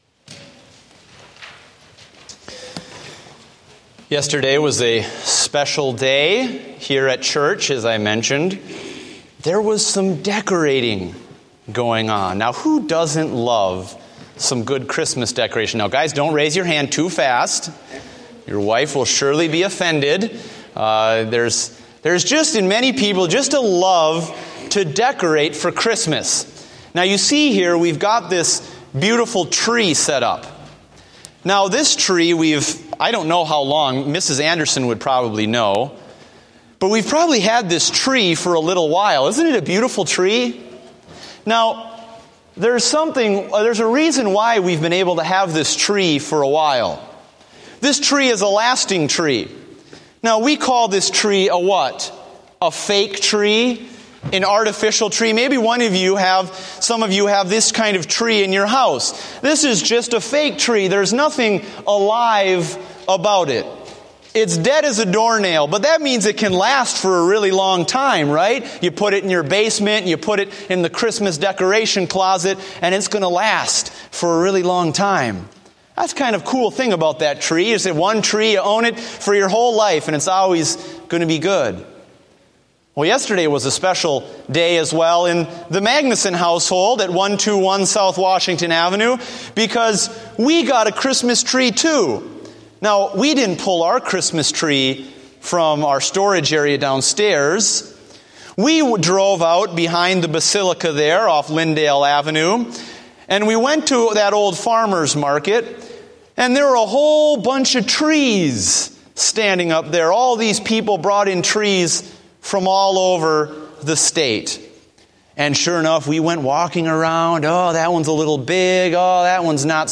Date: November 30, 2014 (Morning Service)